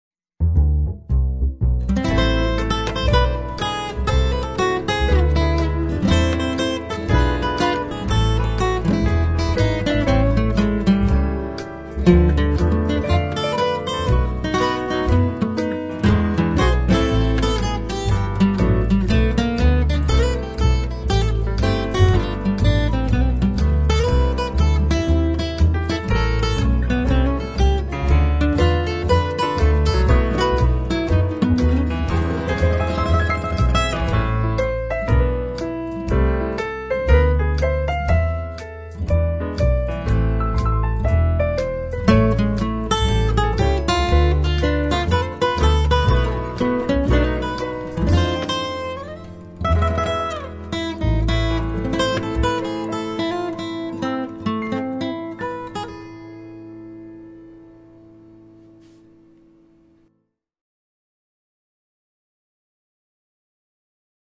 an epic vaudeville
The music parallels the chronology of the action — each song is in a musical style of the era in which it occurs in the story.
Introduction to Act Two - Instrumental